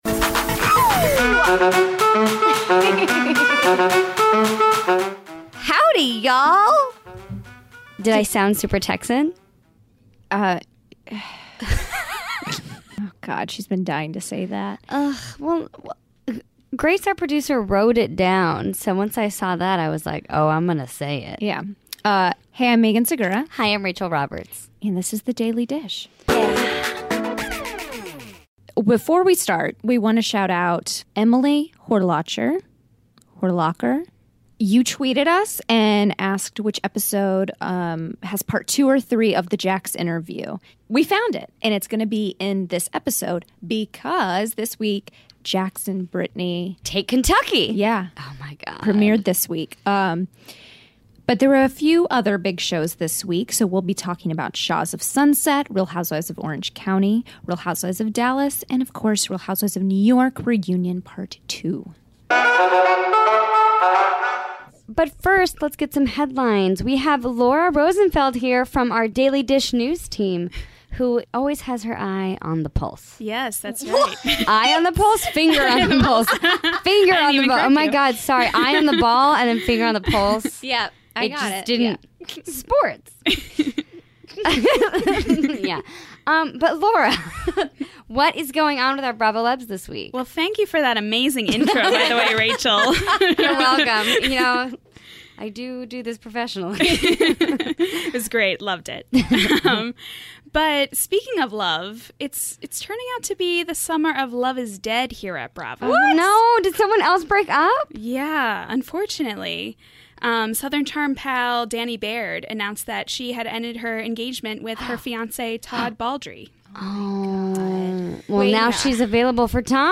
We're digging into the archives for an interview with Jax Taylor talking to us about his new show just after they had finished filming. Plus - Siggy tells us a bit about the new season of RHONJ as we break down the latest trailer.